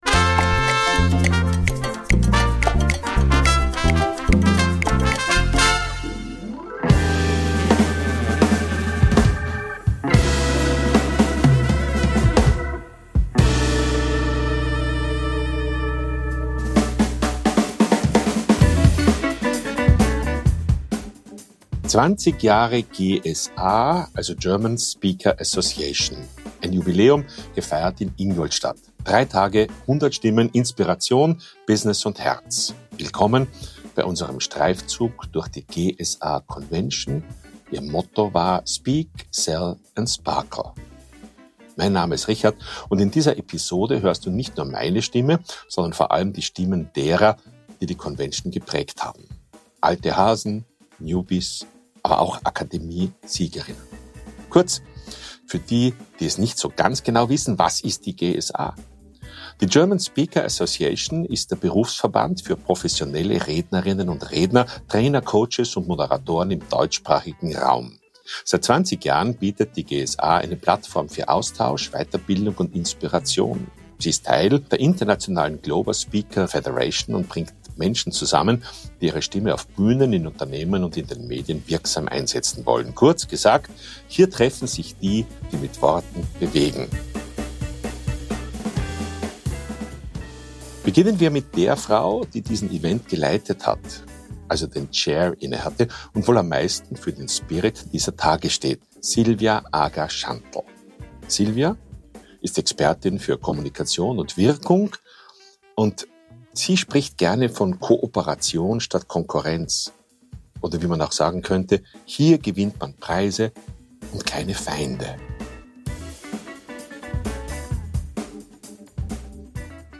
Bunt. Vielfältig. Wunderbar. – Stimmen von der GSA-Convention 2025